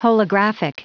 Prononciation du mot holographic en anglais (fichier audio)
Prononciation du mot : holographic